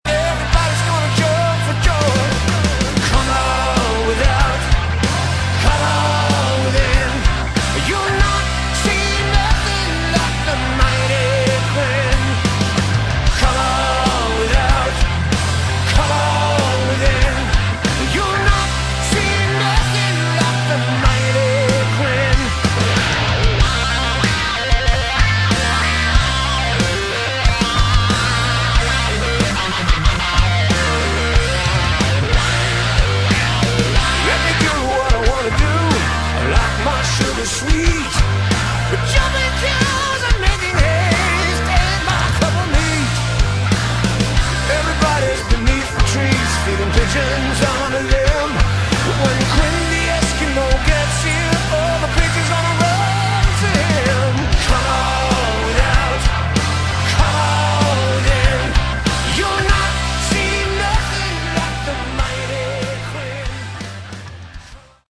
Category: Hard Rock
vocals
keyboards
bass
guitars
drums